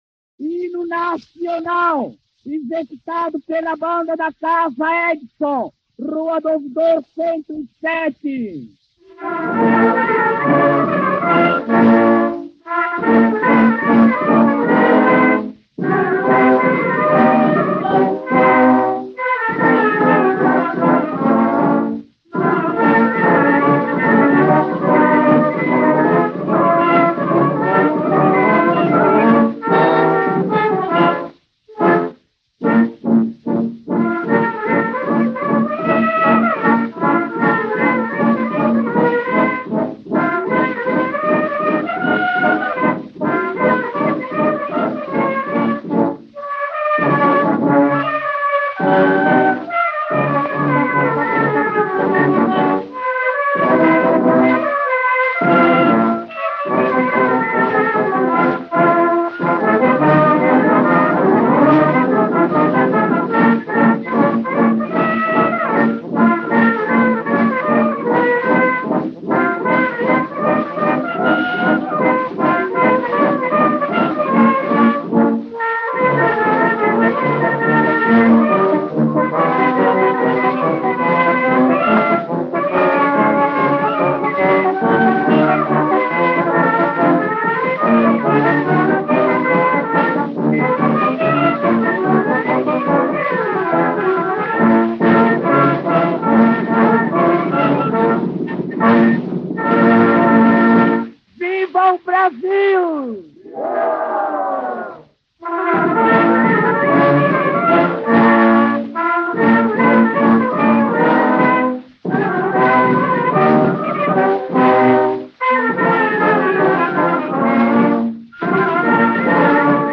Entre as primeiras gravações realizadas no Brasil, encontra-se o Hino Nacional.  De 1902, com a Banda da Casa Edison, a música de Francisco Manuel da Silva, 20 anos antes de a letra de Osório Duque Estrada ser consagrada como a definitiva.